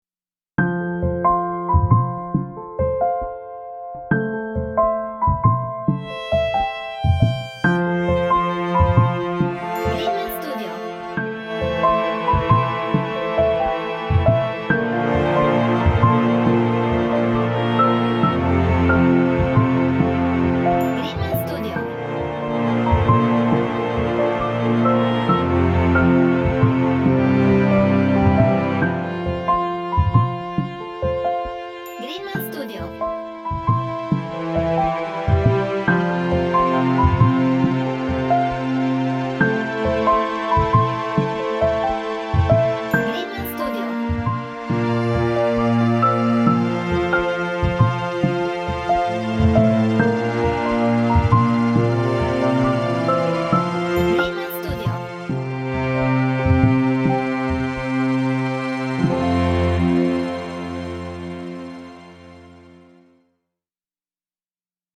Relaxed/Romantic